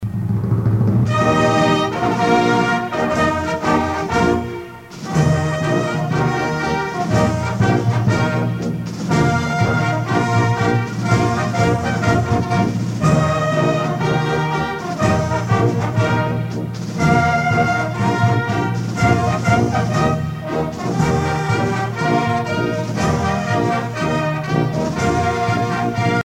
Fonction d'après l'analyste gestuel : à marcher
Usage d'après l'analyste circonstance : militaire